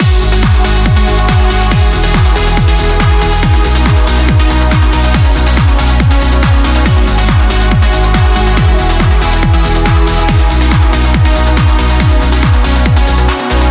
Сжатый и не сжатый микс
На мой вкус резковата ударка, но эт имхо.
Конечно он мощнее звучать будет приплюснутый, прикол в том, чтобы сделать мощно и не пожато